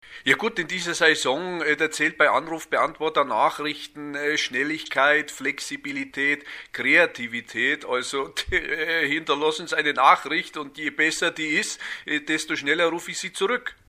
Sprecher & Stimmenimitator